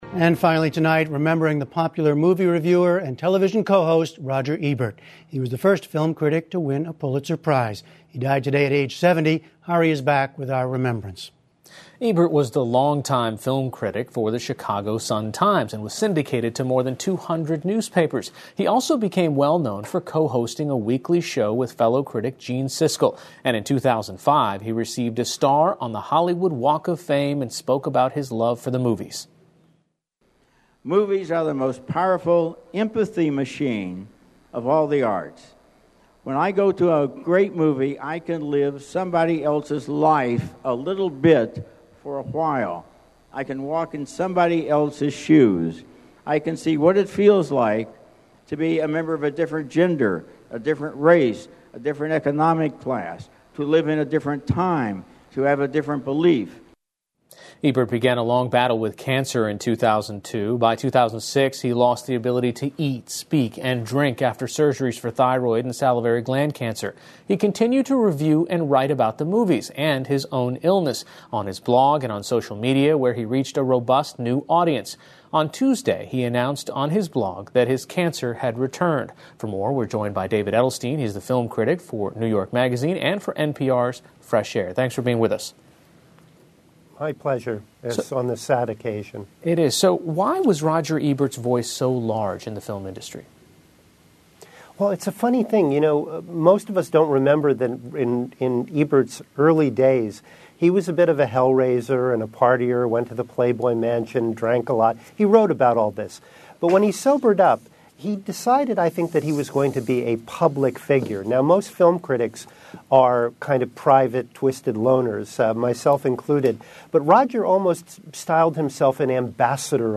英语访谈节目:决定电影命运的著名评论家罗杰·艾伯特